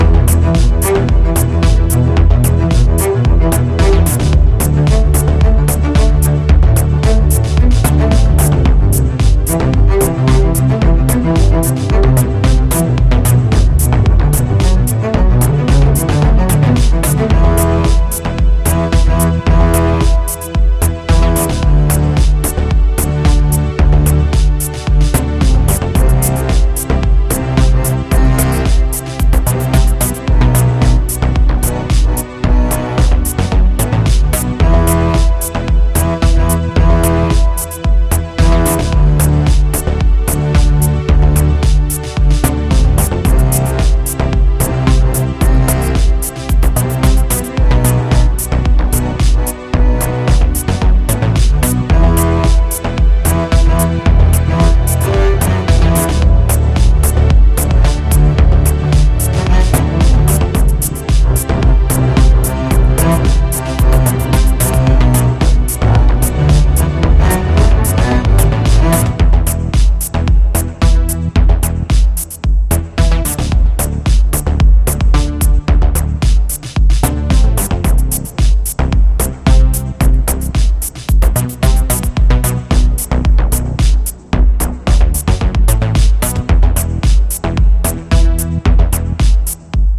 このレーベルから出るだけあって音質もバッチリ。